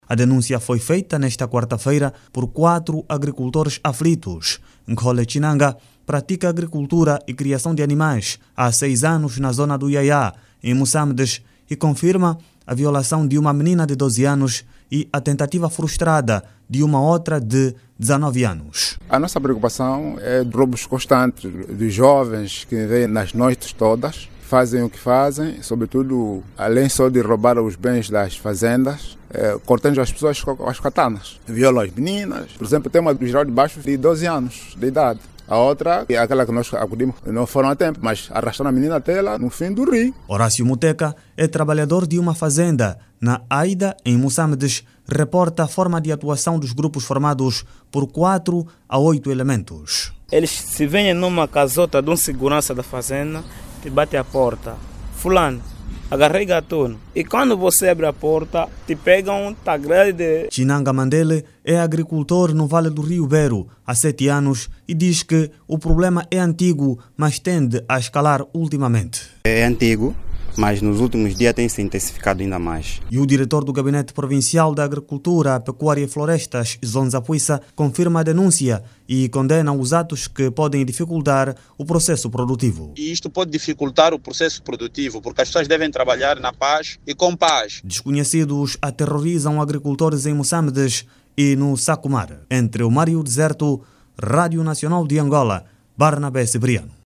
Há denúncias que grupos de jovens armados estão a atacar trabalhadores nas fazendas de Moçâmedes e Sacomar. Os ataques visam, principalmente, o roubo de gado e outras acções criminosas que estão a pôr em causa a segurança dos criadores de gado e agricultores. Clique no áudio abaixo e ouça a reportagem